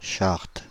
Prononciation
Prononciation Paris: IPA: [ʃaʁt] France (Île-de-France): IPA: /ʃaʁt/ Le mot recherché trouvé avec ces langues de source: français Les traductions n’ont pas été trouvées pour la langue de destination choisie.